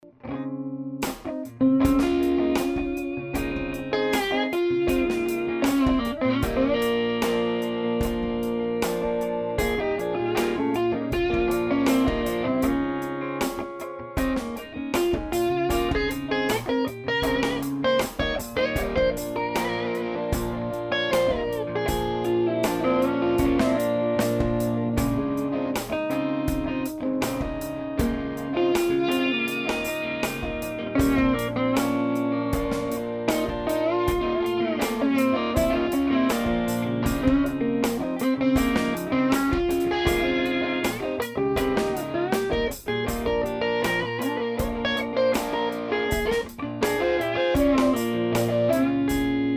335 (Slide)
Signal chain: DIY Teletronix La2a and Pultec EQP-1a, Revolution Redd.47 Preamp, Purple Audio MC76, Altec 438a. Microphones: Vintage Shure Unidyne III, Sennheiser e906 (close) and AKG 414 (room). Speakers: Speed Shop A12Q, Celestion Blue, G12M Heritage. Amps: Various RecProAudio Tweed Deluxe P2P and Studio-Series with NOS Tubes: RCA 6V6GT, RCA 12AX7, RCA 12AY7, RCA 5Y3.
335_slide_black_crowes.mp3